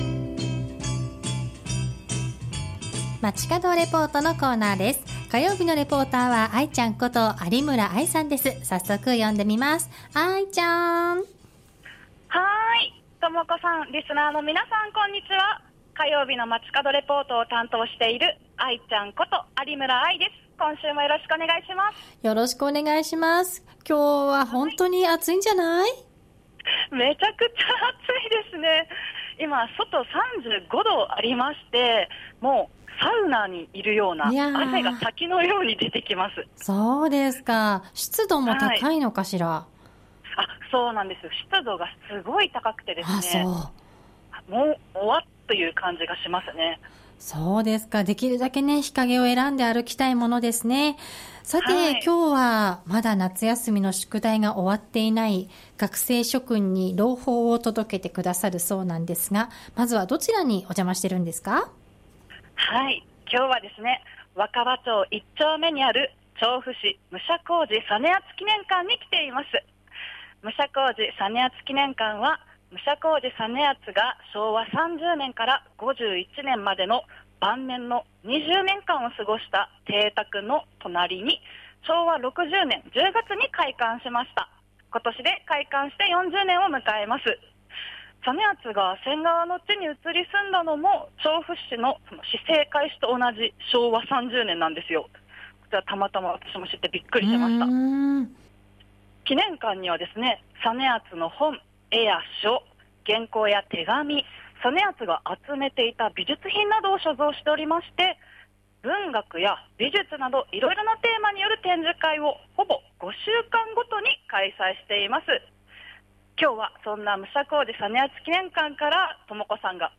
今週は「調布市武者小路実篤記念館」からお届けしました。